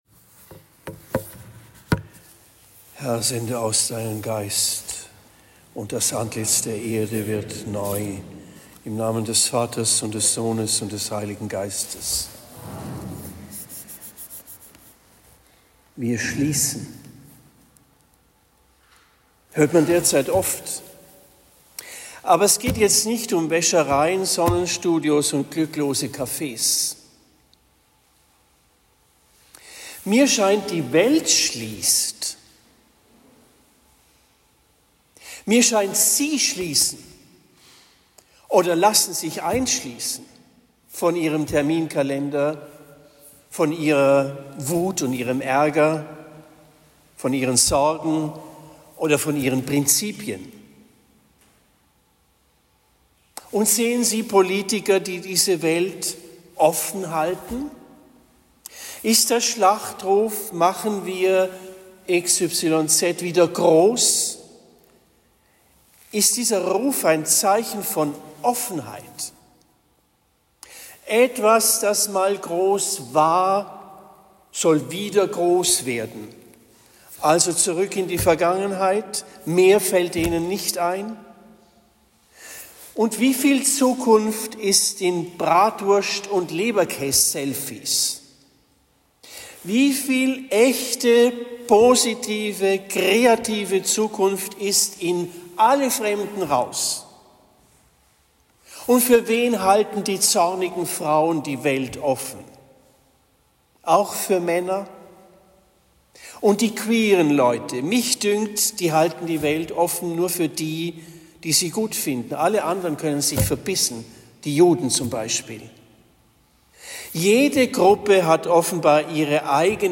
Allerheiligen 2025 Predigt in Trennfeld am Abend des 31. Oktobers 2025